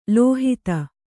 ♪ lōhita